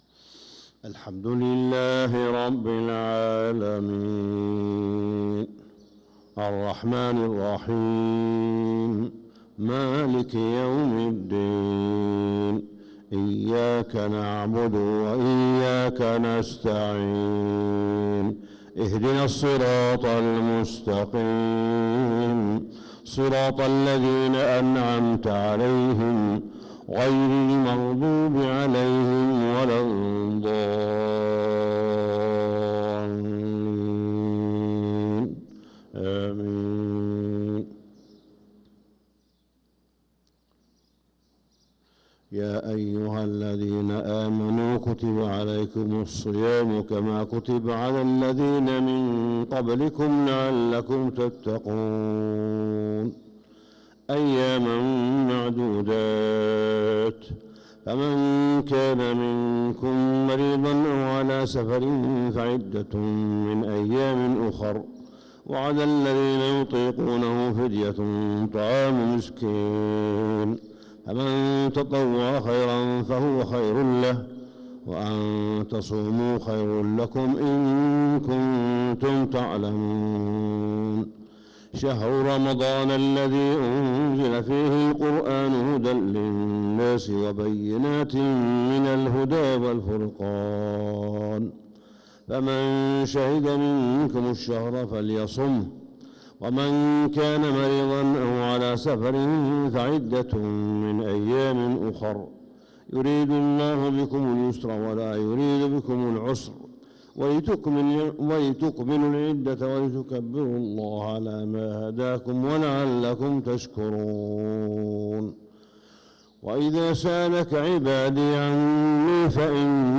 فجر السبت 1-9-1446هـ من سورة البقرة 183-188 | Fajr prayer from Surat al-Baqarah1-3-2025 > 1446 🕋 > الفروض - تلاوات الحرمين